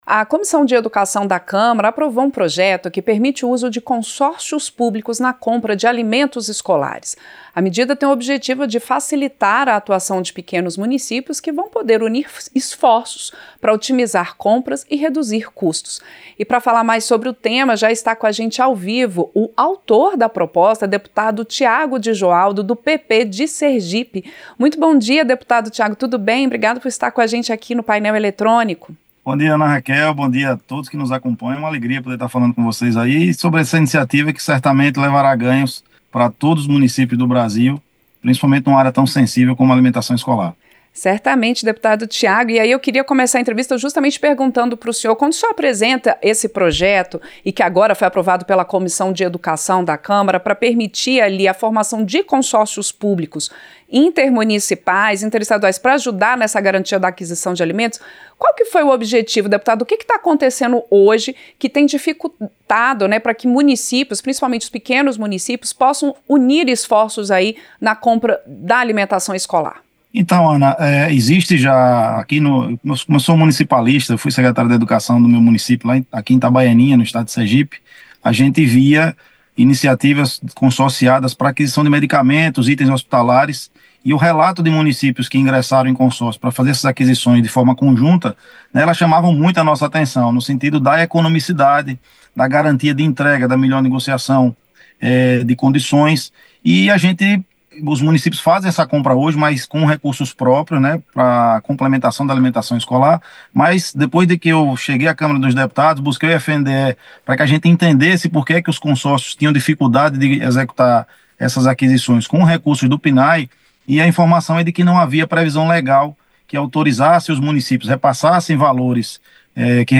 • Entrevista - Dep. Thiago de Joaldo (PP-SE)
Programa ao vivo com reportagens, entrevistas sobre temas relacionados à Câmara dos Deputados, e o que vai ser destaque durante a semana.